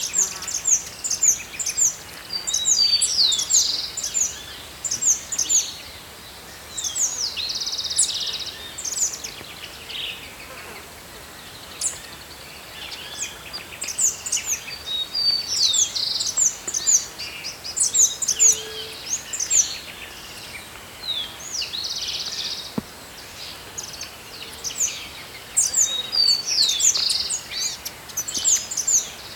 Chestnut-vented Conebill (Conirostrum speciosum)
09:45am - Oído 1 ejemplar vocalizando a 5 metros de altura en un Salix humboltiana.
Location or protected area: Islas en frente a Rosario
Condition: Wild
Certainty: Recorded vocal